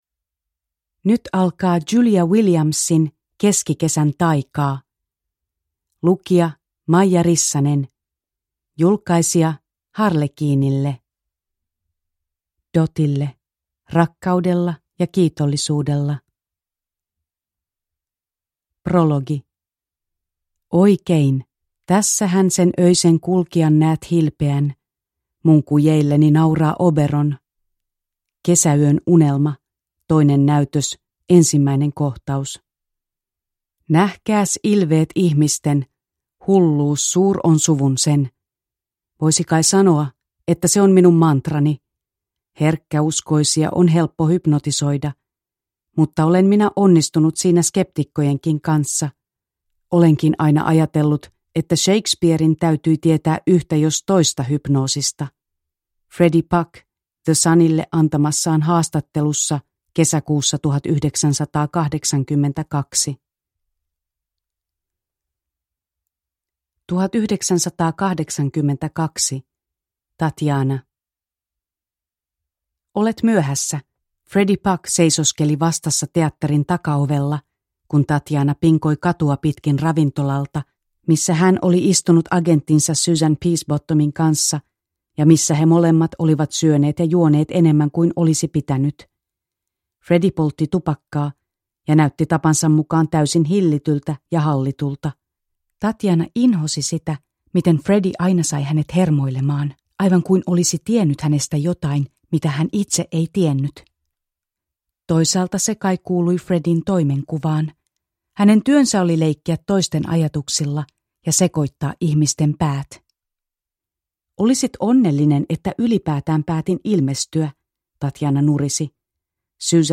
Keskikesän taikaa (ljudbok) av Julia Williams